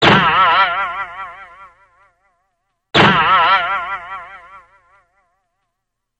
Cartoon - Spring.mp3